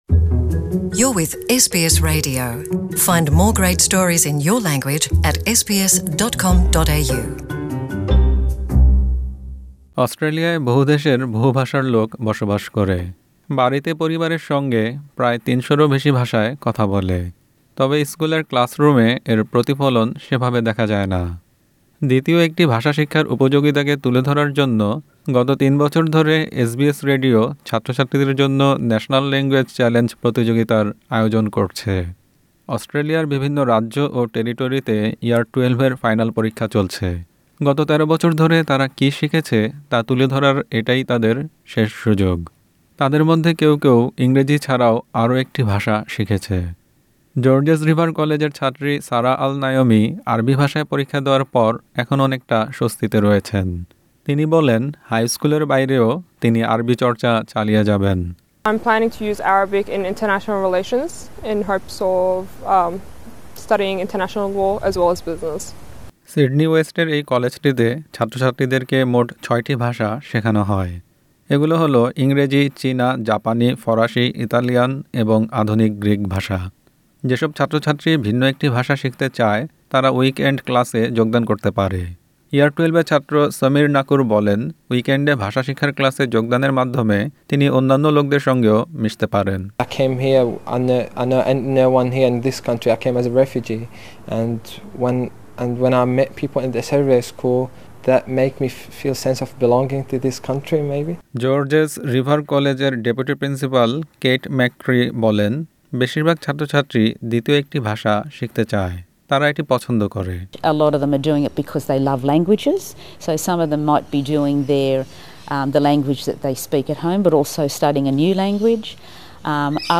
প্রতিবেদনটি বাংলায় শুনতে উপরের অডিও প্লেয়ারটিতে ক্লিক করুন।